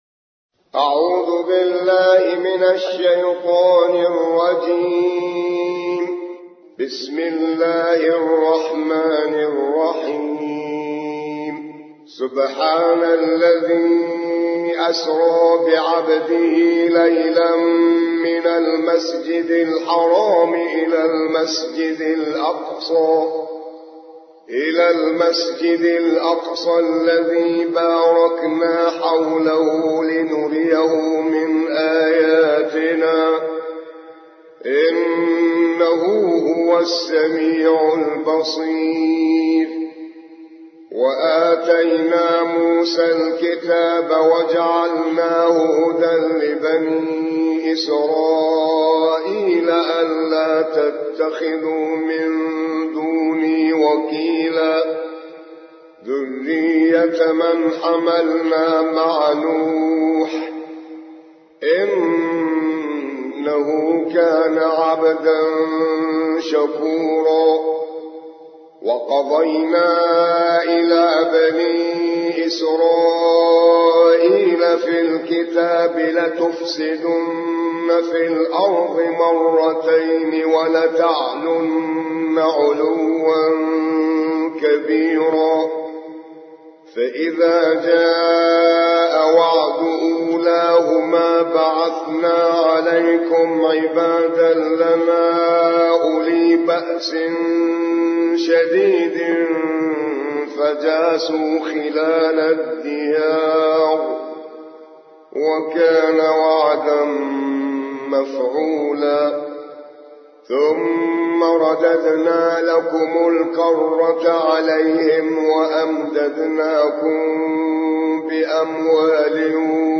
17. سورة الإسراء / القارئ